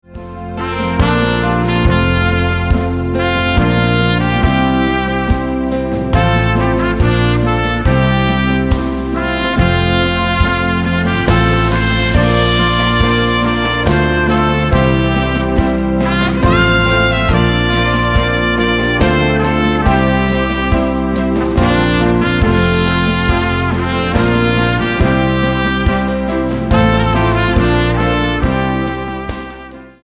Instrumental-CD